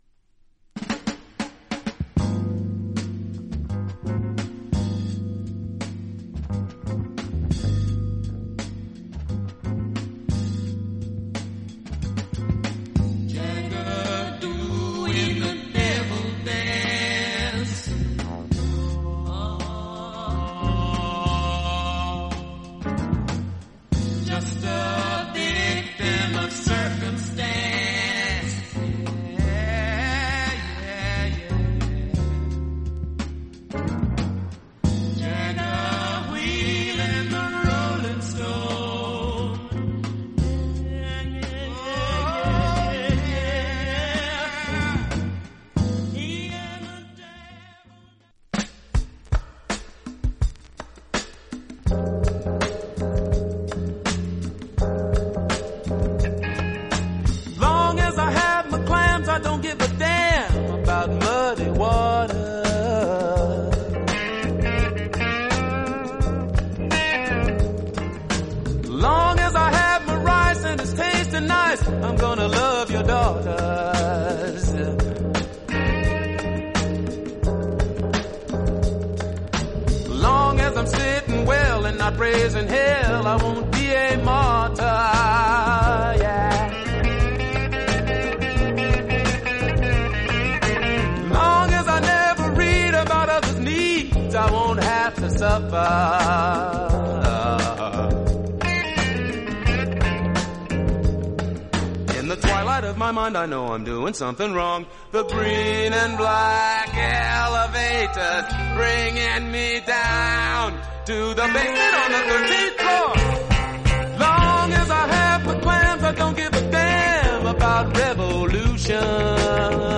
R&B、ソウル
実際のレコードからのサンプル↓